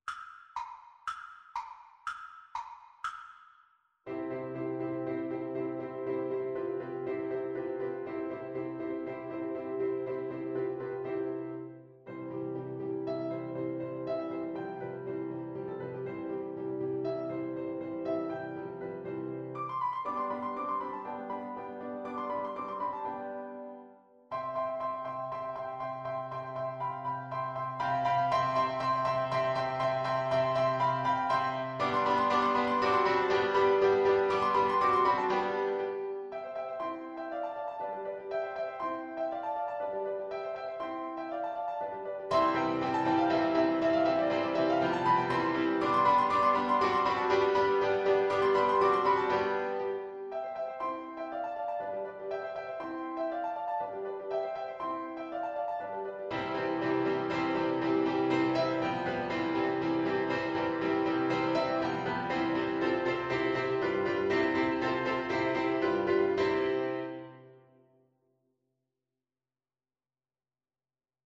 2/4 (View more 2/4 Music)
Classical (View more Classical Flute Music)